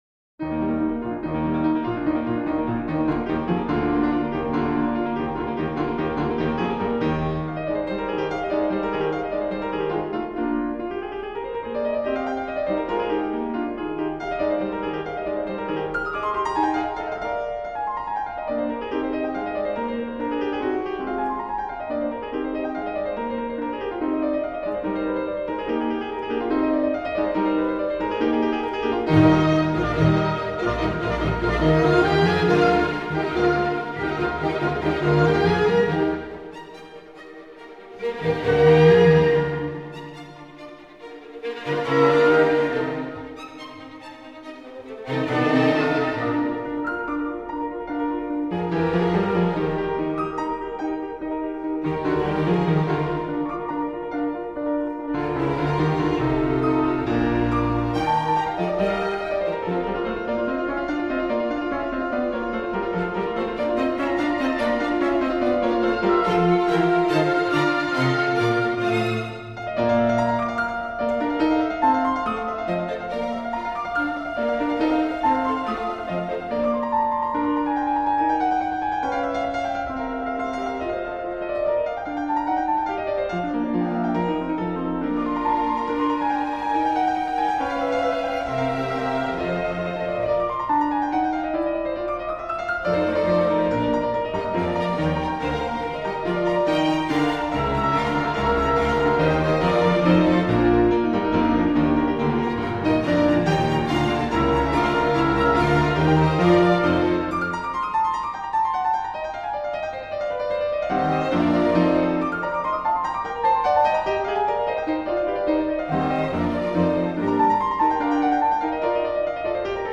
mozart Concerto for Piano and Orchestra No. 9 in E flat KV 271- Rondeau, Presto